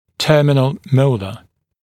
[‘tɜːmɪnl ‘məulə][‘тё:минл ‘моулэ]последний моляр в зубном ряду